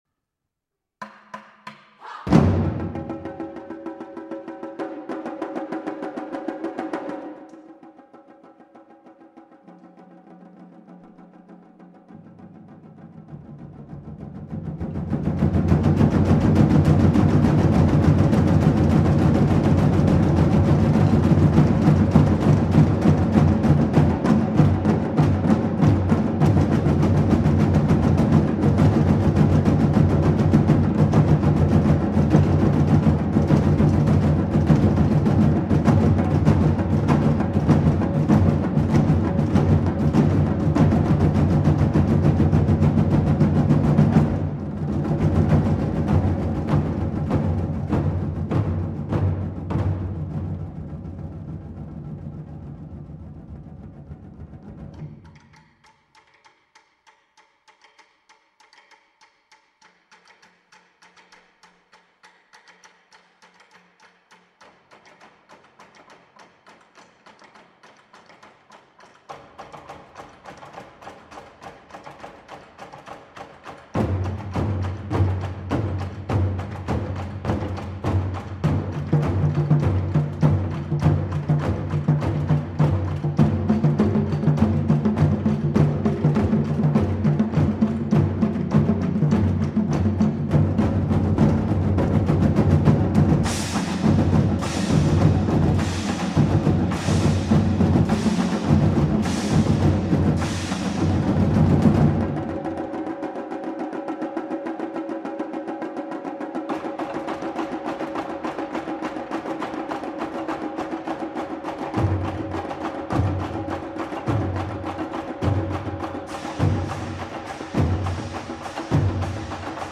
Voicing: 5-20 Percussion